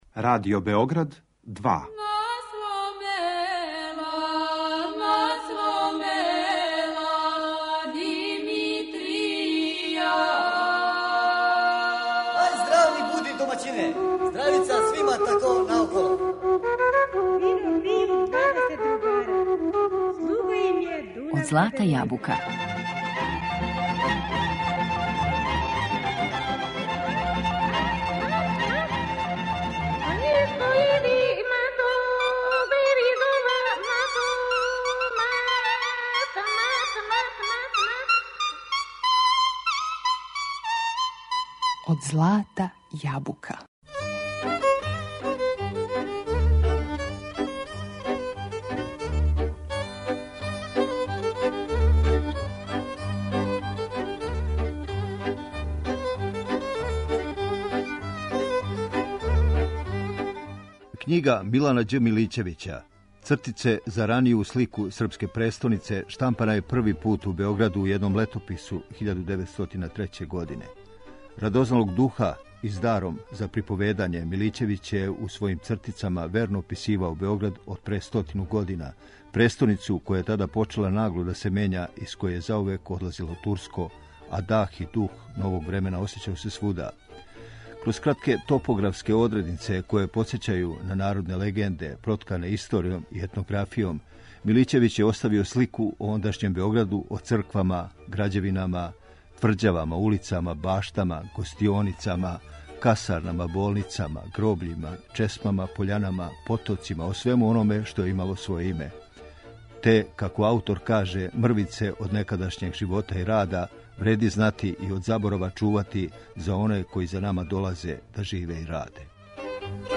Текст приредио и читао новинар